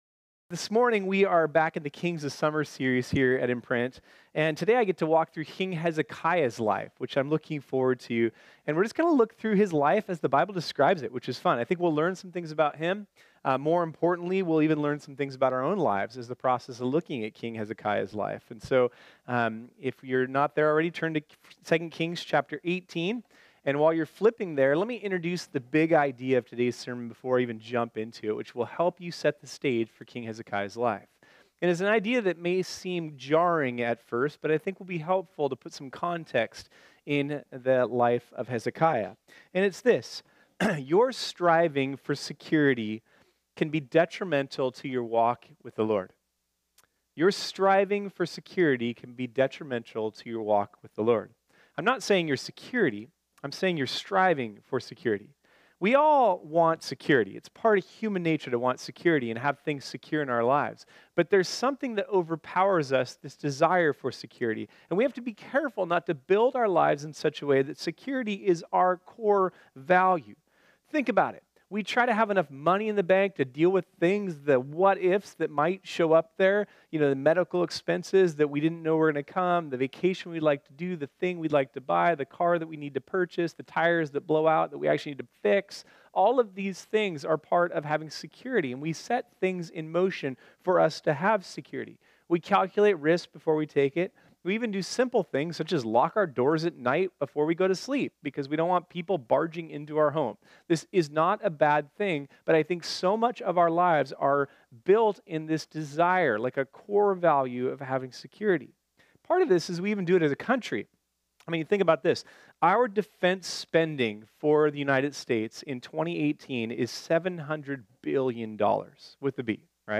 This sermon was originally preached on Sunday, July 29, 2018.